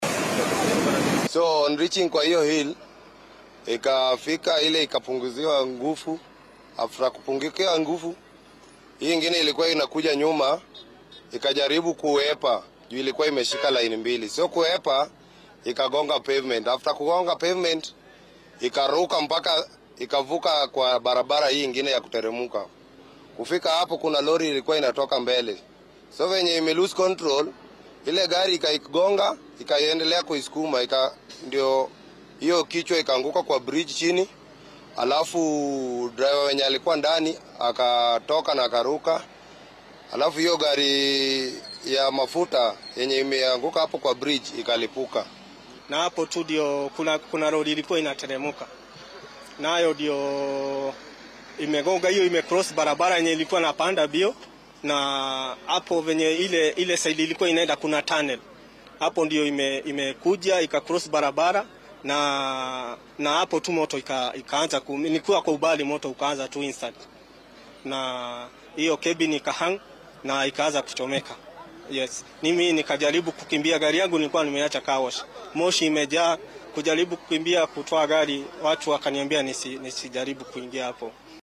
Qaar ka mid ah dad goobjoogayaal ah ayaa ka warbixiyay sida ay wax u dhaceen.